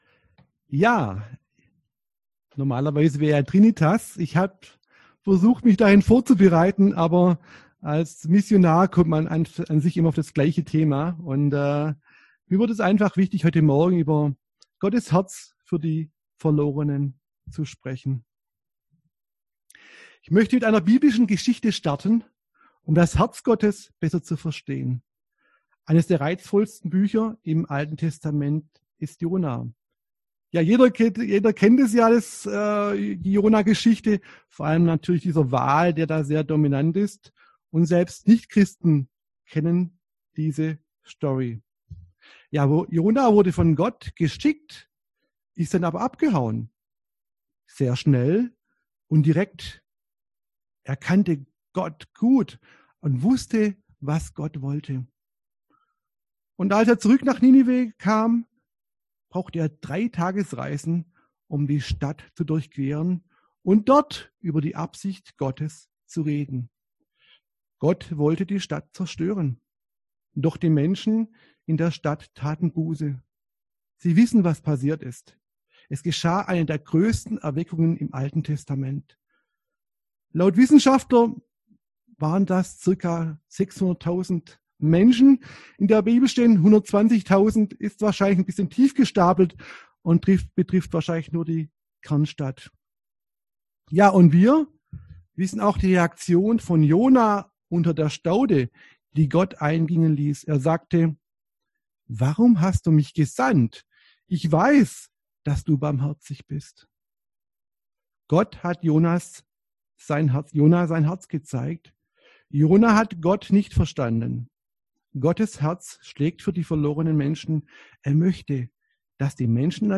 Predigt
im Online-Gottesdienst am Sonntag Trinitatis.